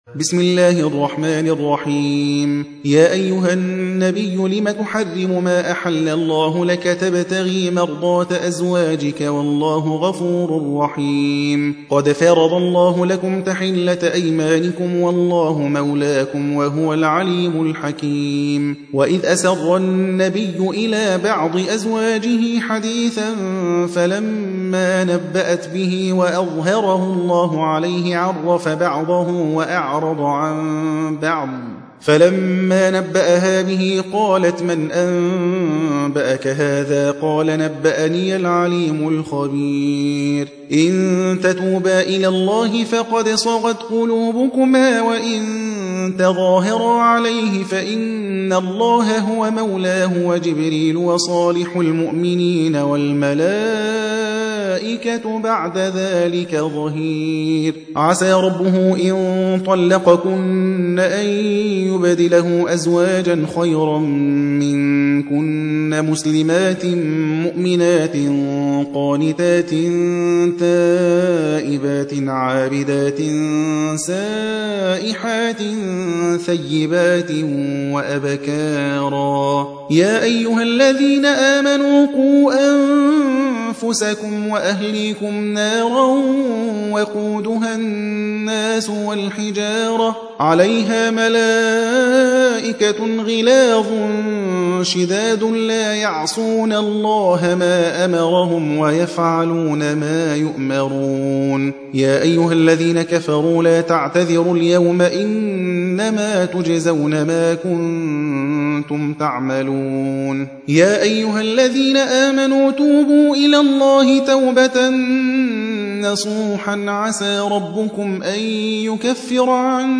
66. سورة التحريم / القارئ